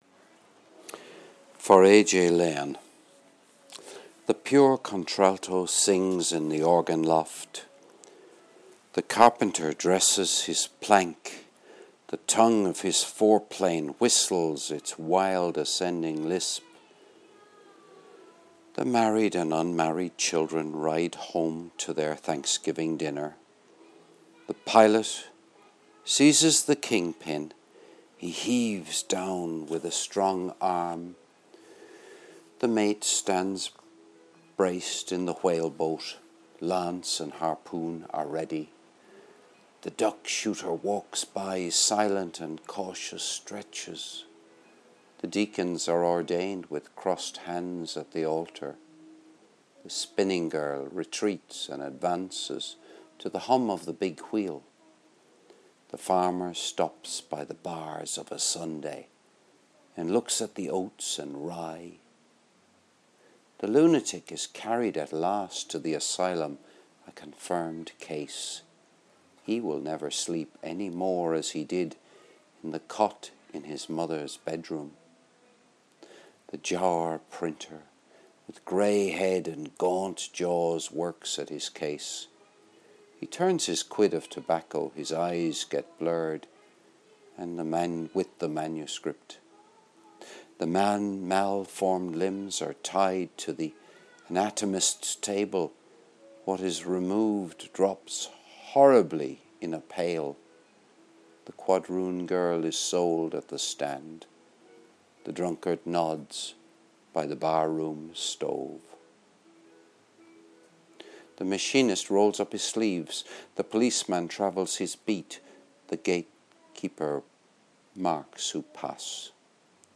Recorded on Friday 11 January 2013 - as rain poured down in Glanmire Cork Ireland.